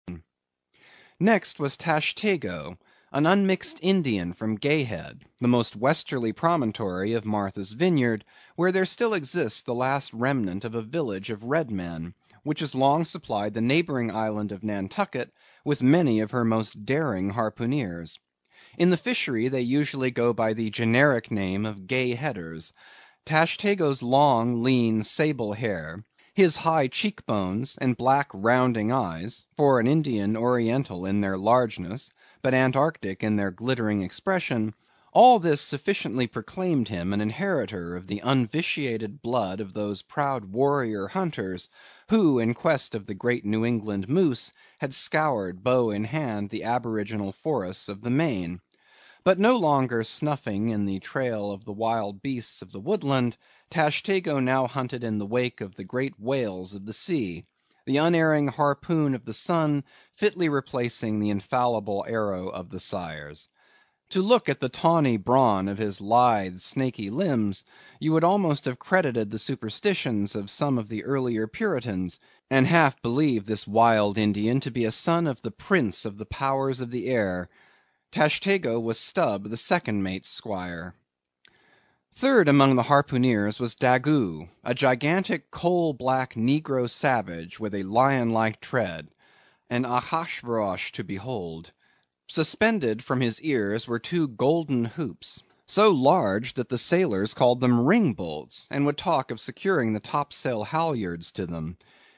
英语听书《白鲸记》第350期 听力文件下载—在线英语听力室